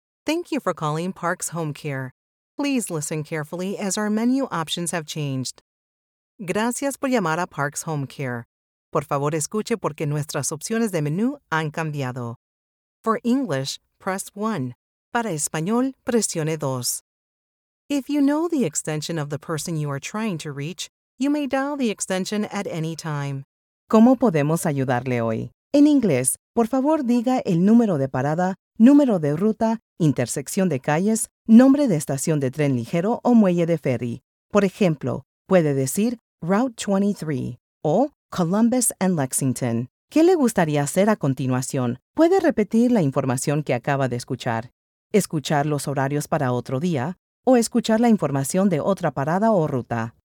Bilingual English and Spanish IVR Demo
Bilingual-IVR-Demo.mp3